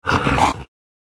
khanat-sounds-sources/sound_library/animals/monsters/mnstr14.wav at 018404caaa8257b614293b15993c3cf34f5117af